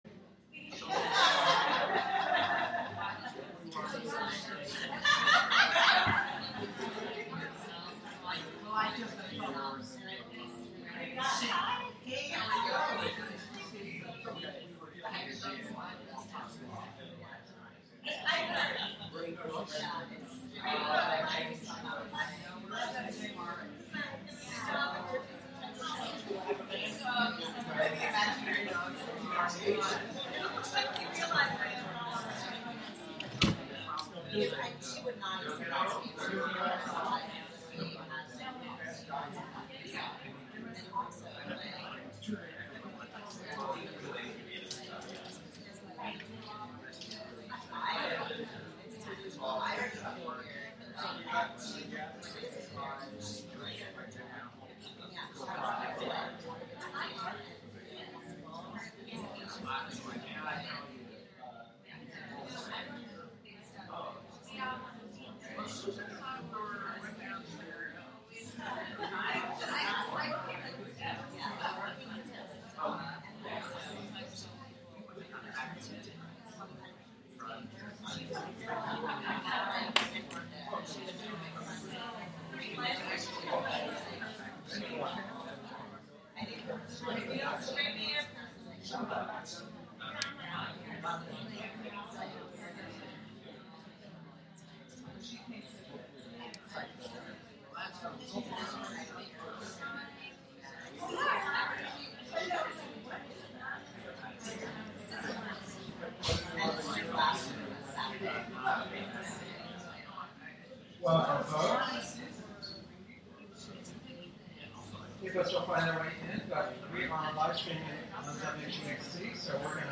Live from The Flow Chart Foundation